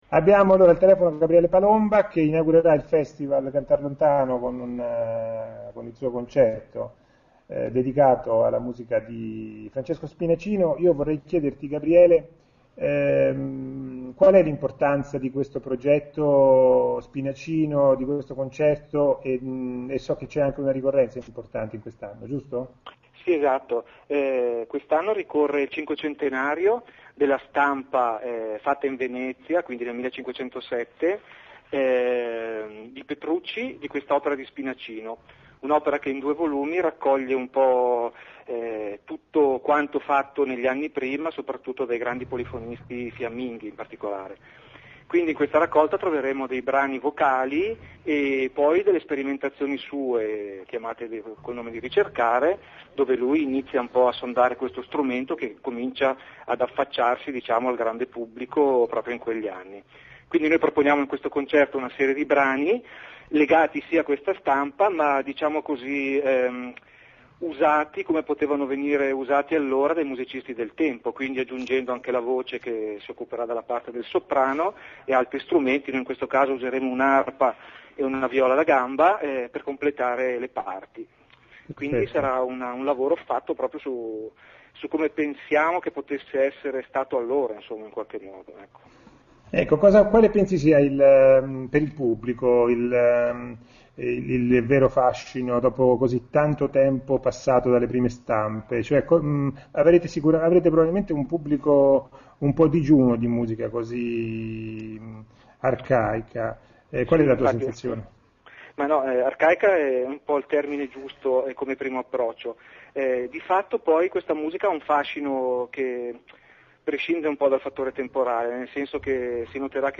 Le interviste agli artisti 2007
Ecco le interviste in formato mp3, concerto per concerto: